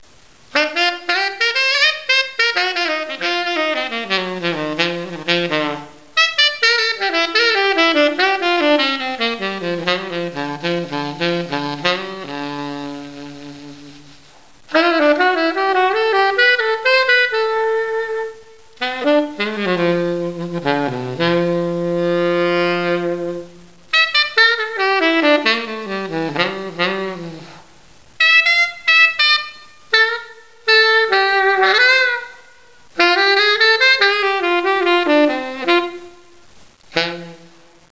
This set-up produces quite a contemporary blues/rock/jazz sound, very easy-blowing, and with the typical Buescher 'big sound', easily going from a whisper to a roar.
I've recorded a very basic 'doodling' sound sample on the sax  ( direct into the PC mic - so not exactly 'hi-fi' ), using my own mouthpiece.
Hear the lyrical top, plus the beefy tenor'ish bottom, alto and tenor in one horn !